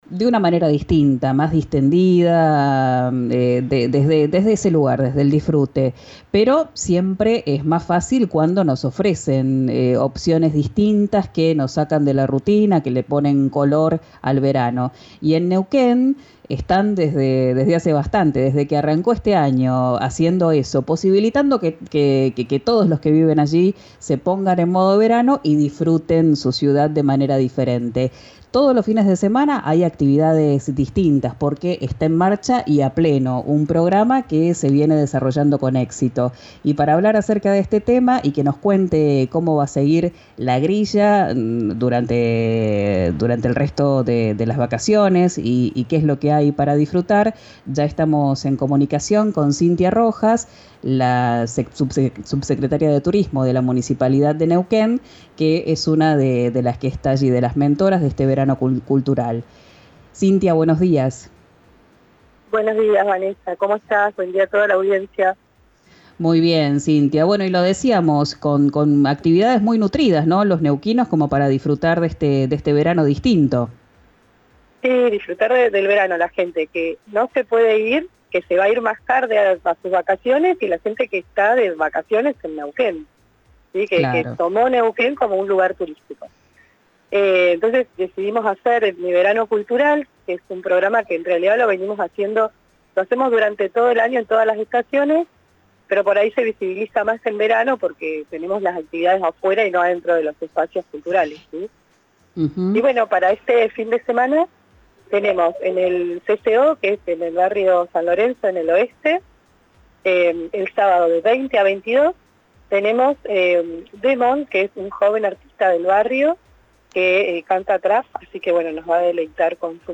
«Mi verano cultural» propone actividades para las personas que aún no se fueron de vacaciones o, por qué no, para quienes deciden vacacionar en la sede de la Fiesta de la Confluencia 2023. La subsecretaria de Cultura de Neuquén capital, Cintia Rojas, habló con «Quién dijo verano», por RÍO NEGRO RADIO, y contó la grilla para este fin de semana: rap, música electrónica, rock, danza, magia y literatura.